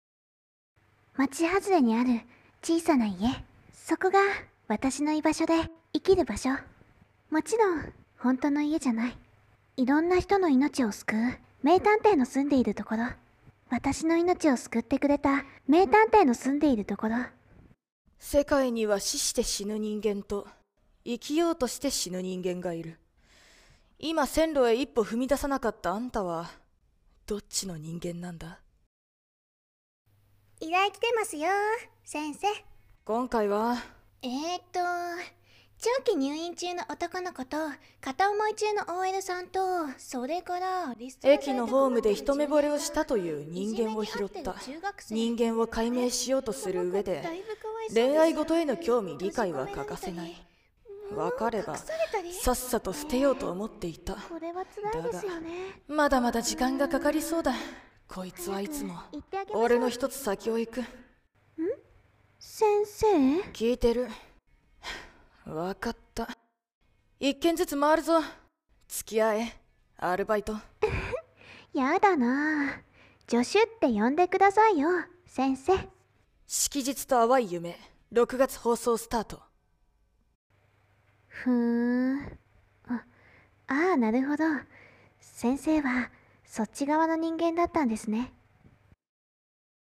【アニメCM風声劇】式日と淡い夢 探偵()×助手()